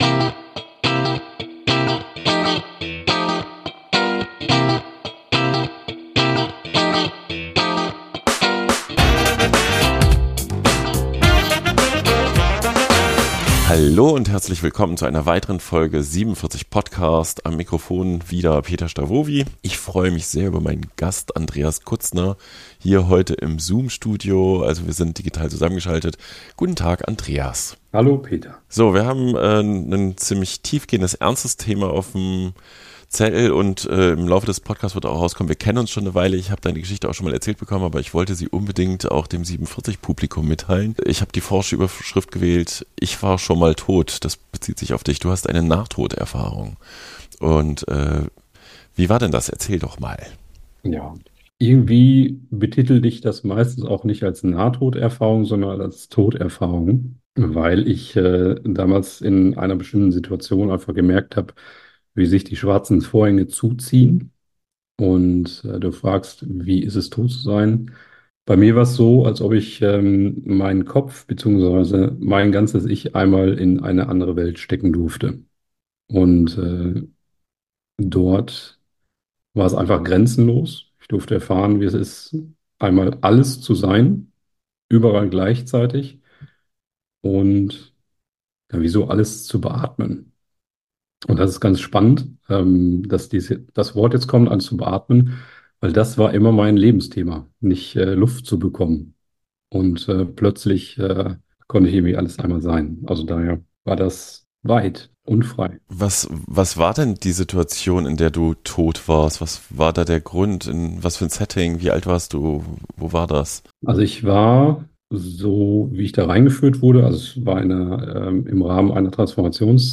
Im Gespräch mit 7VIERZIG erzählt er von seiner Erfahrung, wie es ist, tot zu sein – und seiner Entwicklung zu einer völlig neuen Berufsrichtung.